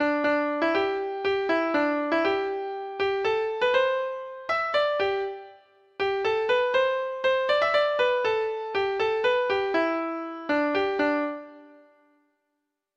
Folk Songs from 'Digital Tradition' Letter G Geordie
Treble Clef Instrument  (View more Intermediate Treble Clef Instrument Music)